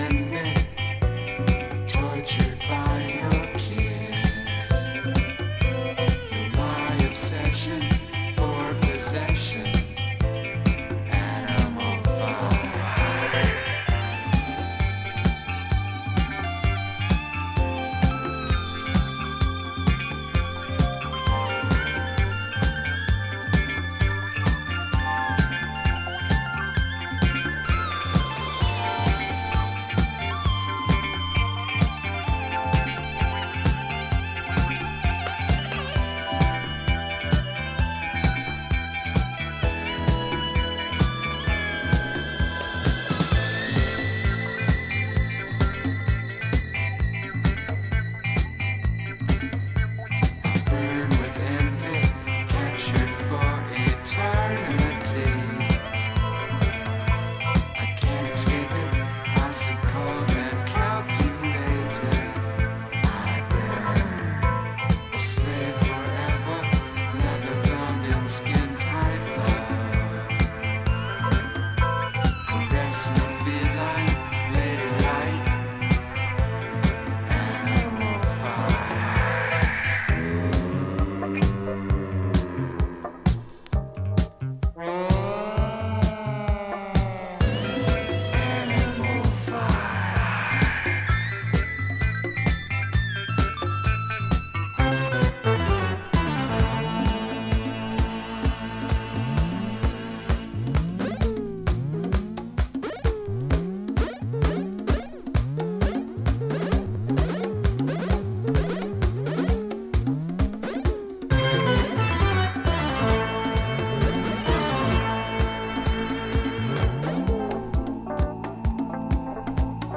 este tesouro da música Disco à atenção
essencialmente instrumental